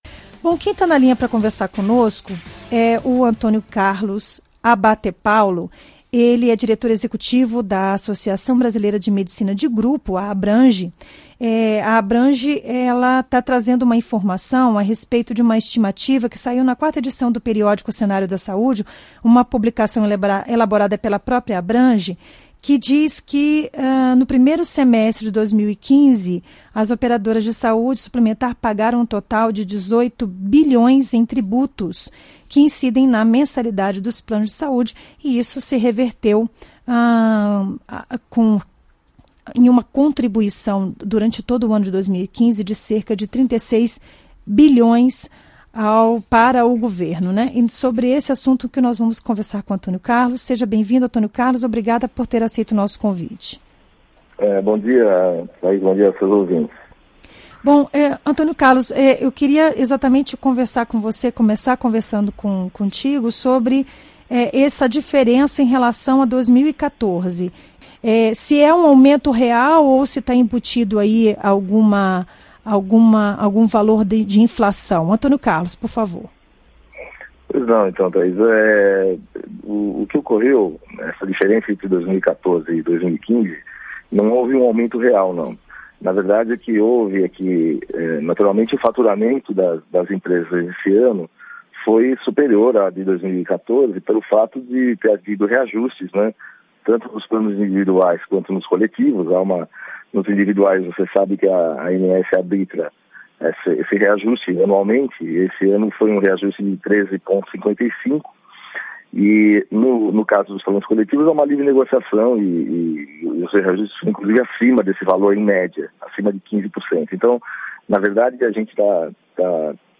Planos de saúde, taxas e desperdícios Entenda o assunto ouvindo a entrevista na íntegra no player acima.